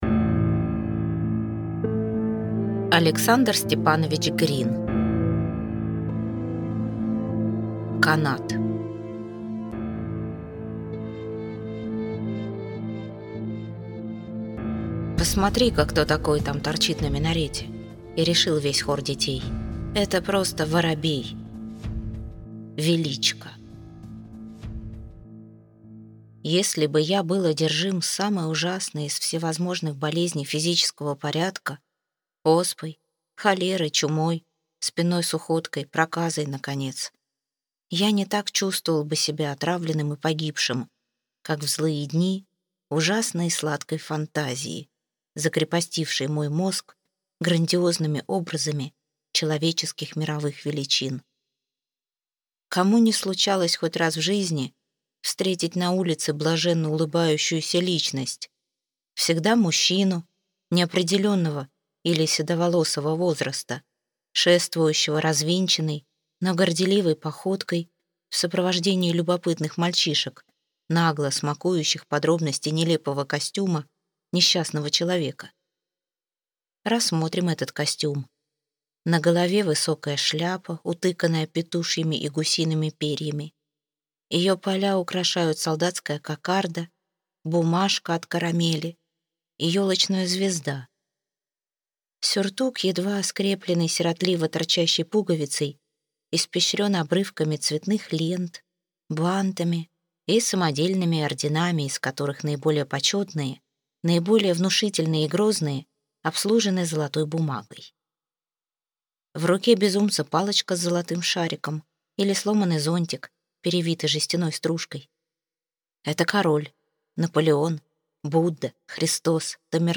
Аудиокнига Канат | Библиотека аудиокниг
Прослушать и бесплатно скачать фрагмент аудиокниги